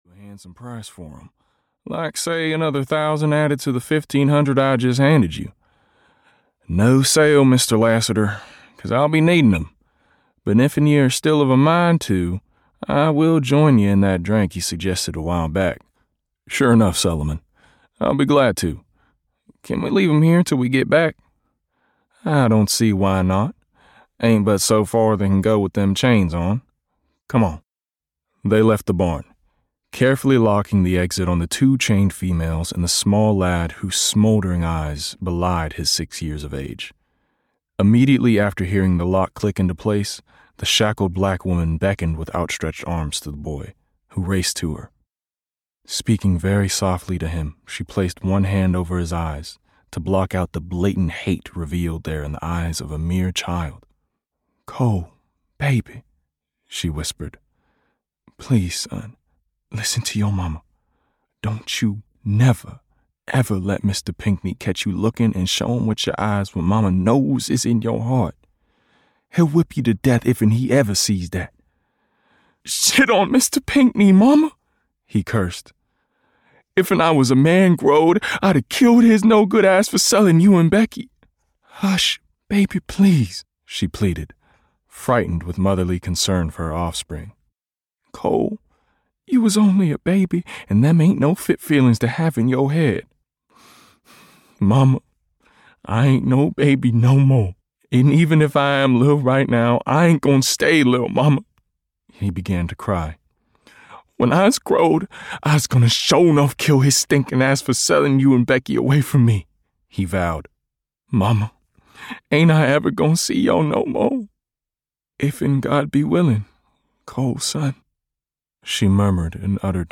Coal (EN) audiokniha
Ukázka z knihy